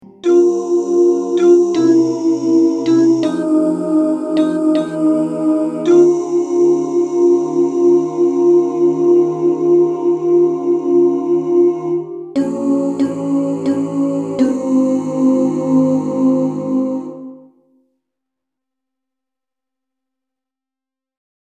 Key written in: F Minor
Type: Other mixed
Comments: Take this at a nice easy ballad tempo.